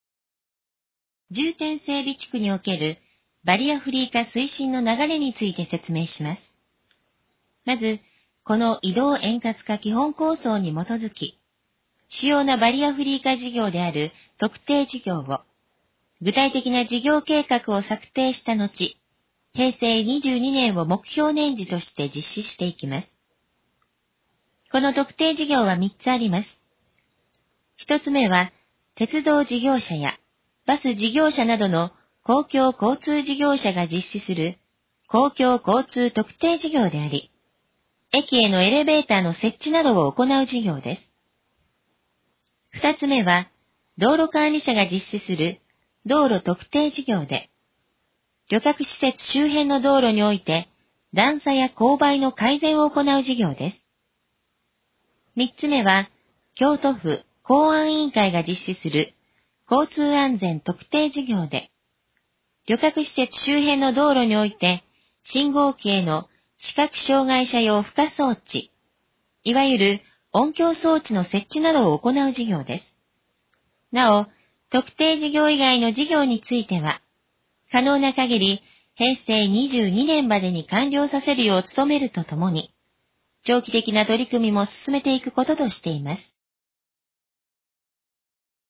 このページの要約を音声で読み上げます。
ナレーション再生 約173KB